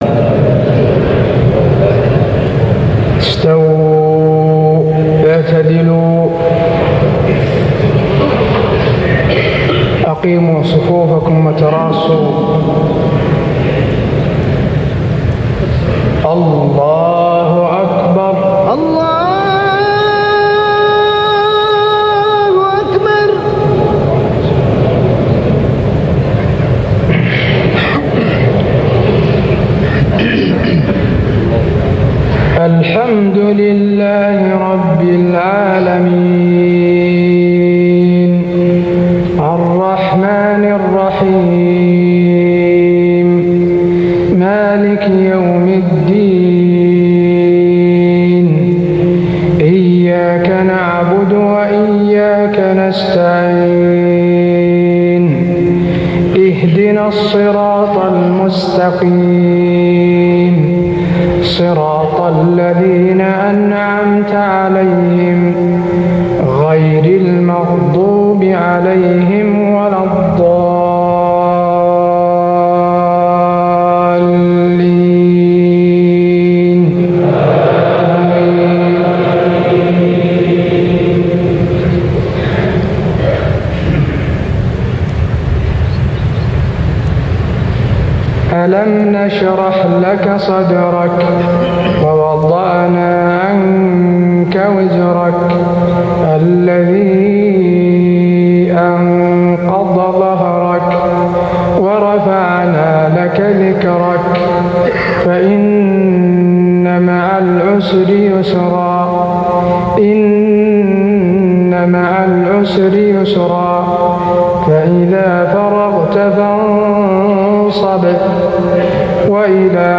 صلاة المغرب 1425هـ سورتي الشرح و العصر > 1425 🕌 > الفروض - تلاوات الحرمين